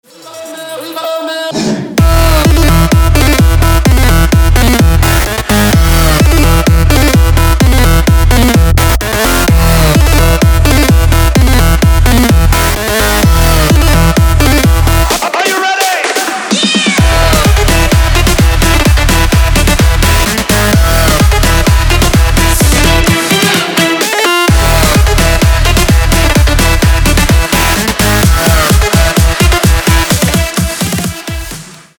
• Качество: 256, Stereo
Electronic
EDM
club
electro